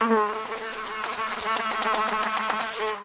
fly1.ogg